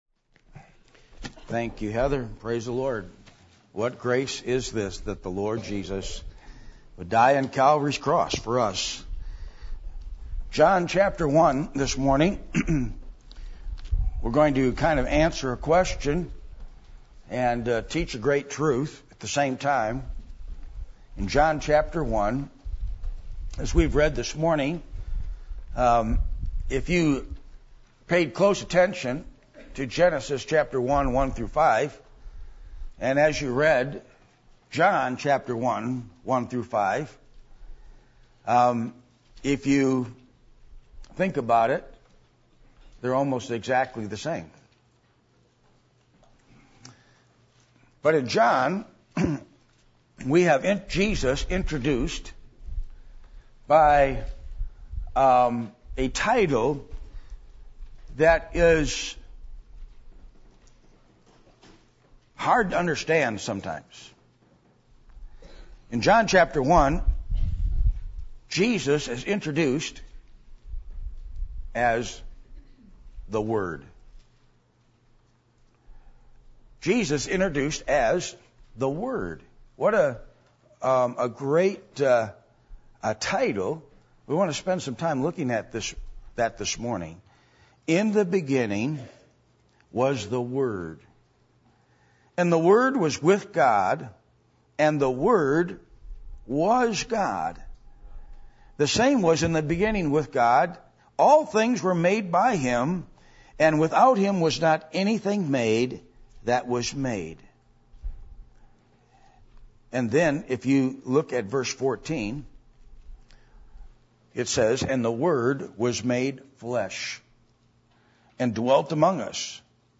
John 1:1-18 Service Type: Sunday Morning %todo_render% « A Picture Of Worship